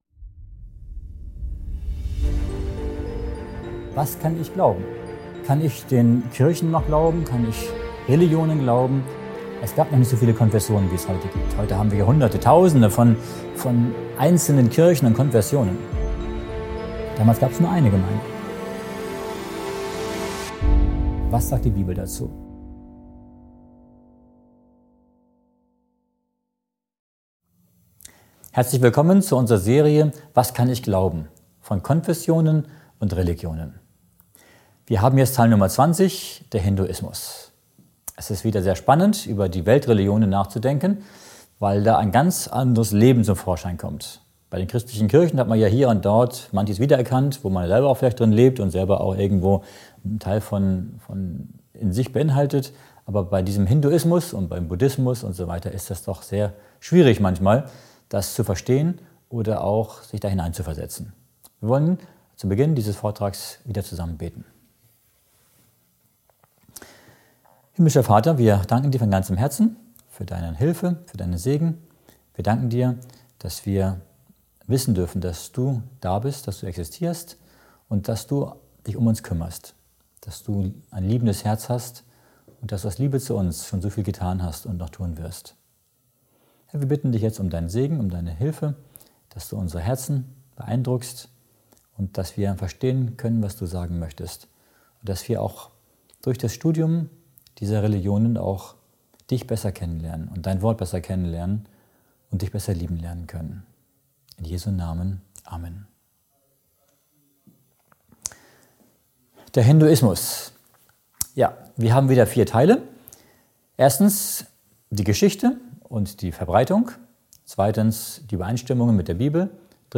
In dieser spannenden Folge der Serie „Was kann ich glauben?“ wird der Hinduismus beleuchtet. Der Vortrag untersucht seine Ursprünge, Glaubensrichtungen und die Unterschiede zur Bibel.